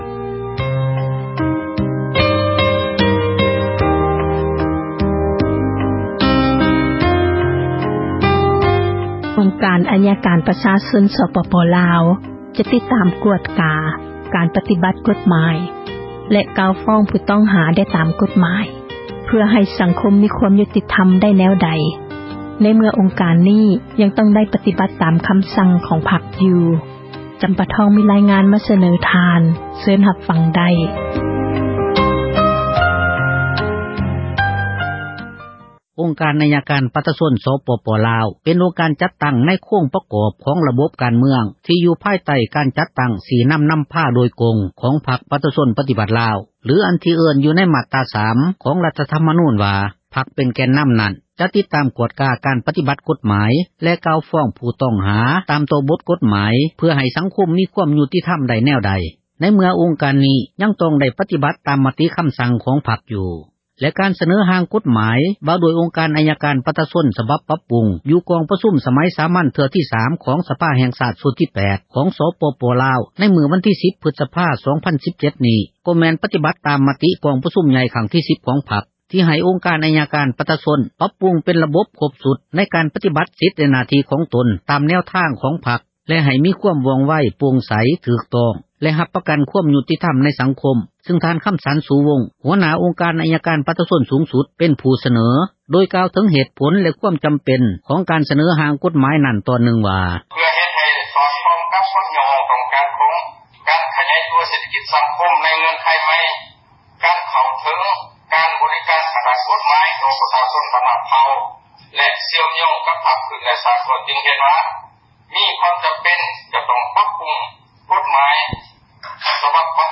ແລະການສເນີຮ່າງກົດໝາຍ ວ່າດ້ວຍອົງການ ອັຍການ ປະຊາຊົນ ສະບັບປັບປຸງ ຢູ່ກອງປະຊຸມ ສະໄໝສາມັນ ເທື່ອທີ 3 ຂອງສະພາ ແຫ່ງຊາດ ຊຸດທີ 8 ຂອງສປປລາວ ໃນມື້ວັນທີ 10 ພຶສພາ 2017 ນີ້ ກໍແມ່ນ ປະຕິບັດ ຕາມມະຕິ ກອງປະຊຸມໃຫຍ່ ຄັ້ງທີ 10 ຂອງພັກ ທີ່ໃຫ້ອົງການ ອັຍການ ປະຊາຊົນ ປັບປຸງ ເປັນຣະບົບ ຄົບຊຸດ ໃນການ ປະຕິບັດສິດ ແລະ ໜ້າທີ່ຂອງຕົນ ຕາມແນວທາງ ຂອງພັກ ແລະ ໃຫ້ມີຄວາມວ່ອງໄວ, ໂປ່ງໃສ, ຖືກຕ້ອງ ແລະຮັບປະກັນ ຄວາມຍຸຕິທັມ ໃນສັງຄົມ ຊຶ່ງທ່ານ ຄໍາສານ ສຸວົງ ຫົວໜ້າ ອົງການອັຍການ ປະຊາຊົນ ສູງສຸດ ເປັນຜູ້ສເນີ ໂດຍກ່າວເຖິງ ເຫດຜົລ ແລະຄວາມຈໍາເປັນ ຂອງການສເນີ ຮ່າງກົດໝາຍ ນັ້ນຕອນນຶ່ງວ່າ: ສຽງ...
ອັນທີ່ວ່າຮ່າງກົດໝາຍ ຖືກປັບປຸງປ່ຽນແປງ ໄປຕາມແນວທາງ, ແຜນນະໂຍບາຍ ແລະການຊີ້ນໍາ ຂອງພັກນັ້ນ ແມ່ນສະແດງ ຢູ່ໃນພິທີເປີດ ກອງປະຊຸມ ສະພາແຫ່ງຊາດ ເມື່ອວັນທີ 25 ເມສາ ແລະ ໃນພິທີປິດ ກອງປະຊຸມສະພາ ໃນມື້ວັນທີ 18 ພຶສພາ 2017 ຊຶ່ງ ຍານາງ ປານີ ຢາທໍ່ຕູ້ ປະທານ ສະພາແຫ່ງຊາດ ກໍໄດ້ກ່າວຕອນນຶ່ງວ່າ: ສຽງ...